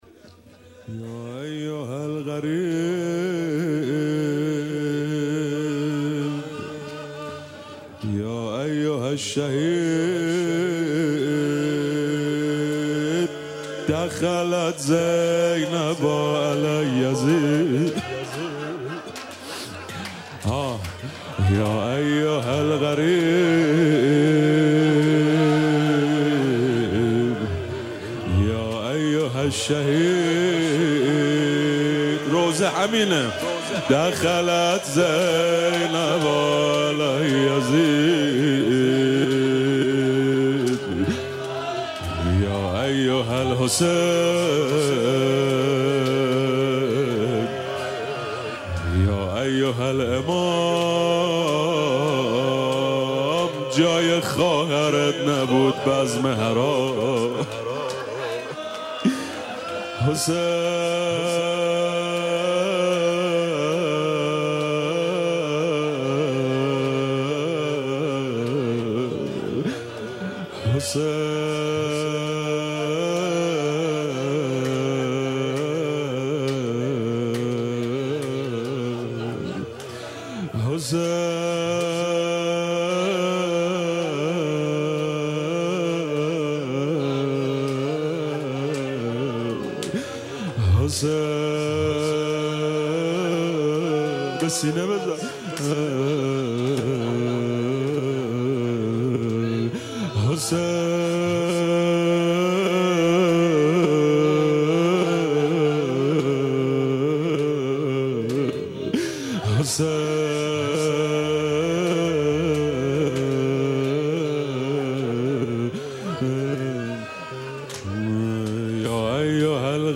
شب چهاردهم ماه رمضان/ ۲۶ فروردین ۴۰۱ زمینه مداحی امام حسین علیه السلام اشتراک برای ارسال نظر وارد شوید و یا ثبت نام کنید .